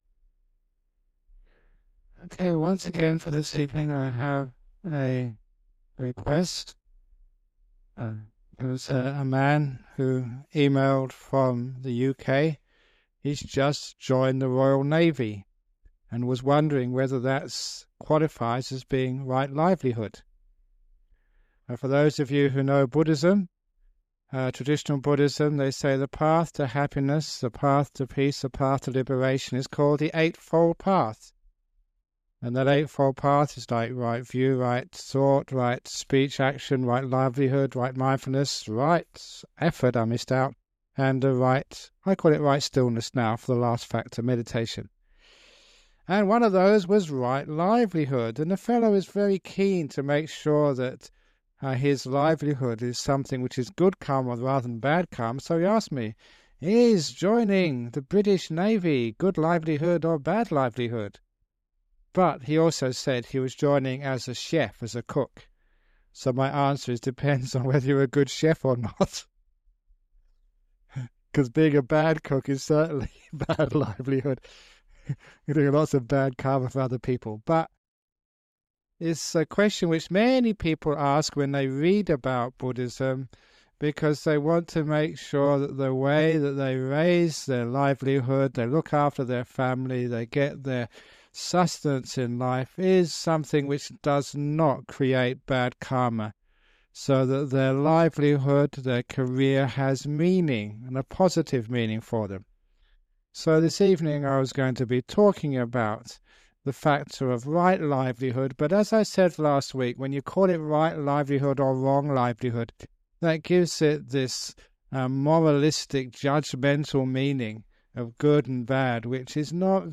České znění vytvořeno díky štědré podpoře posluchačů skrze sbírku